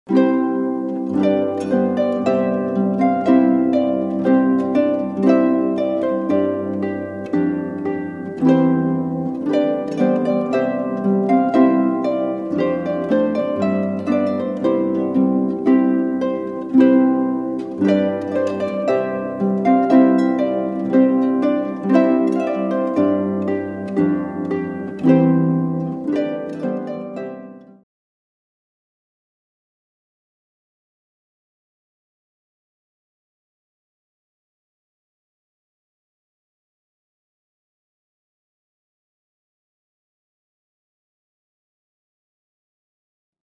Wedding Harpist
harp arrangements